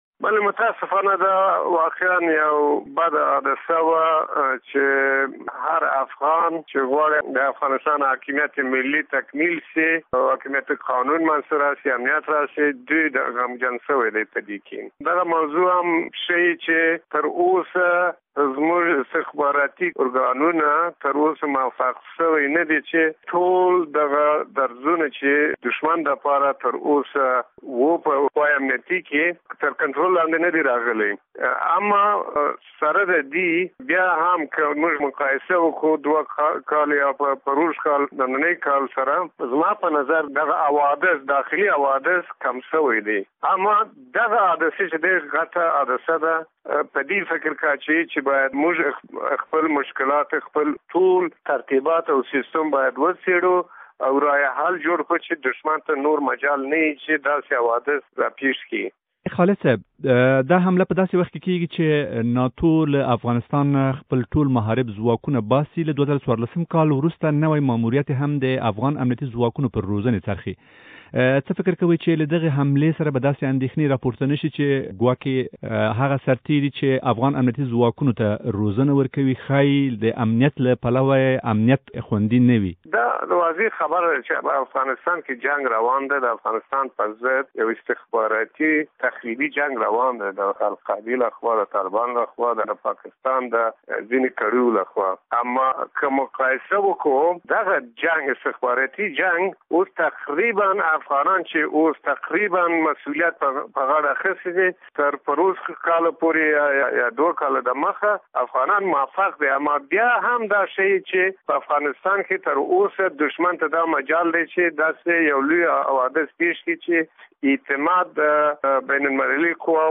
له جنرال عبدالهادي خالد سره مرکه